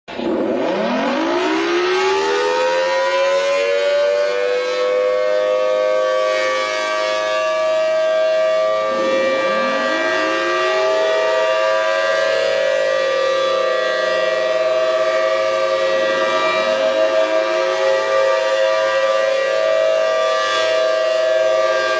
Звук сирены, предупреждающий о цунами (вариант 2)